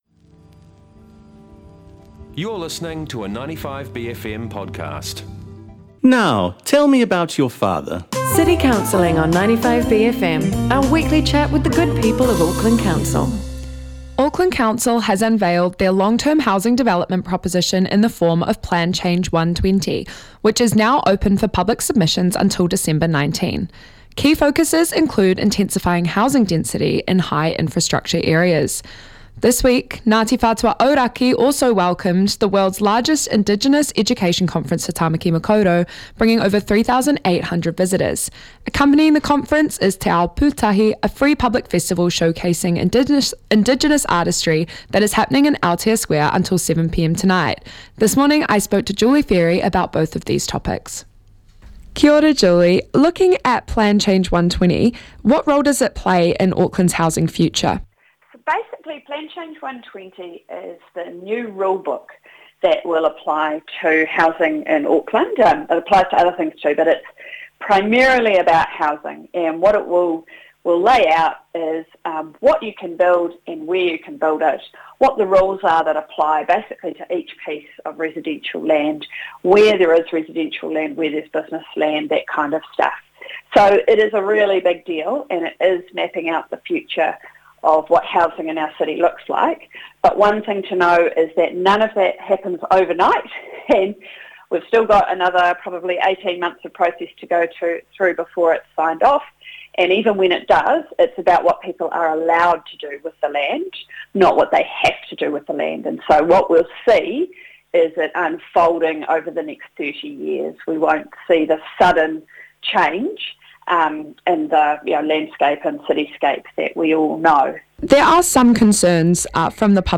speaks to Councillor Julie Fairey about the folllowing for this week's edition of City Counselling